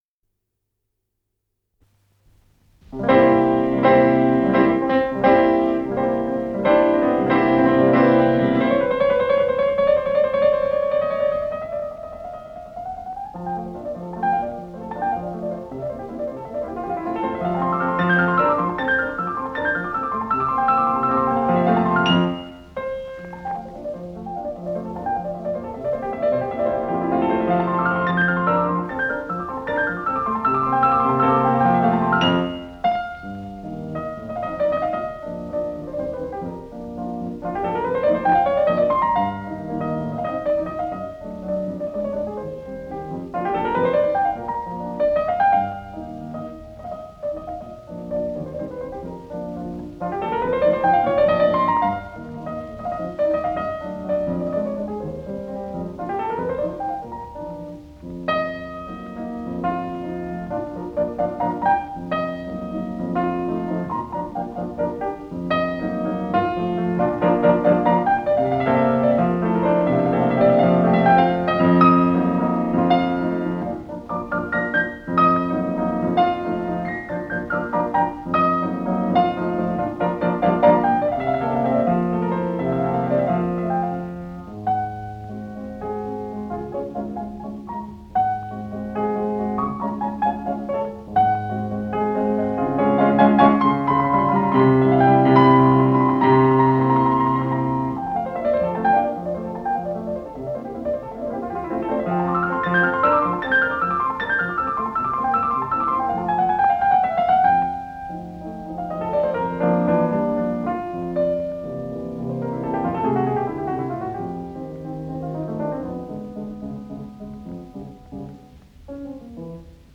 с профессиональной магнитной ленты
ИсполнителиАльфред Корто - фортепино